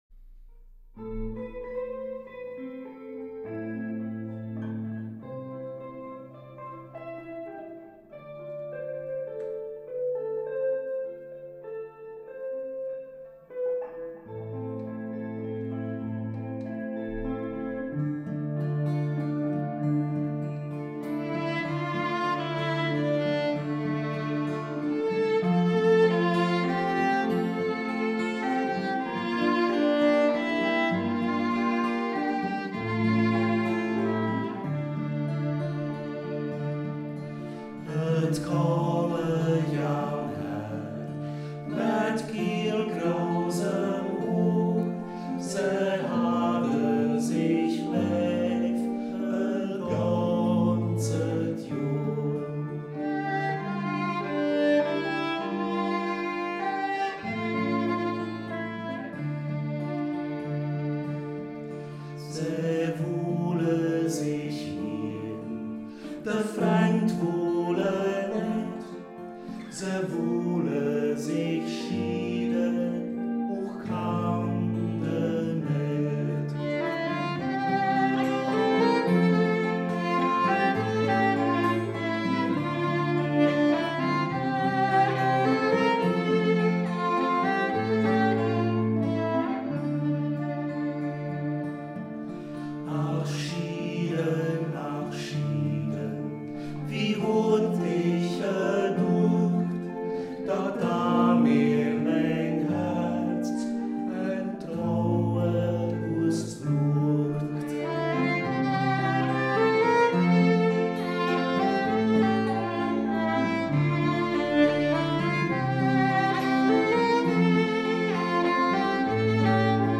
Volkslied
Anmerkungen zum Text, Worterkl�rungen Der letzte Vers wird pianissimo wiederholt.